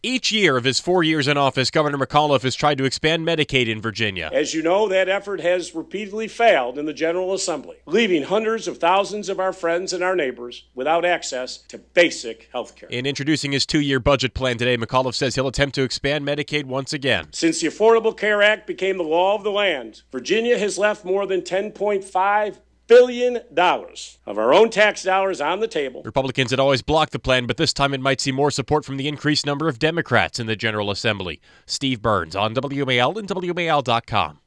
McAuliffe addressed the House and Senate finance committees Monday morning to unveil a two-year spending proposal.